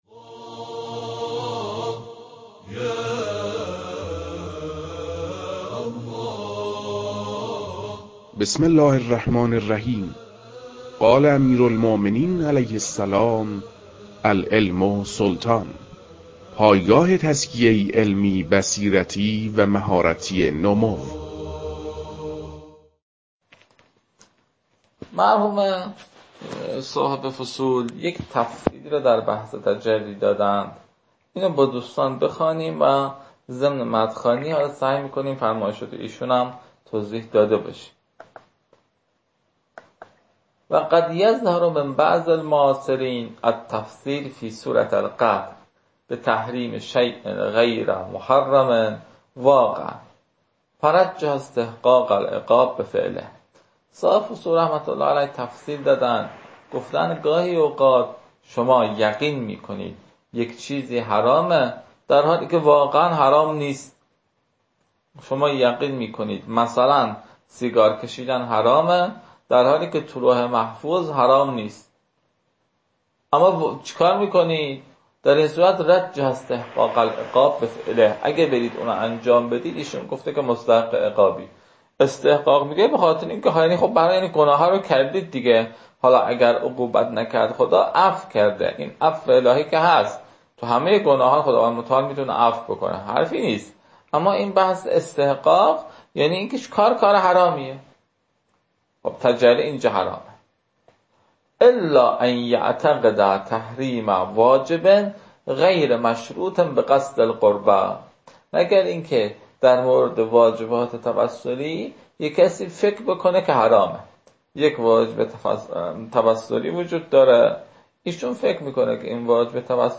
در این بخش، فایل های مربوط به تدریس مبحث رسالة في القطع از كتاب فرائد الاصول متعلق به شیخ اعظم انصاری رحمه الله